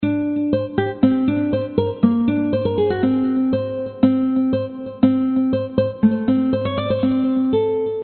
描述：经典的吉他riff原声120bpm
标签： 原声 经典 作曲家 吉他 循环 riff
声道立体声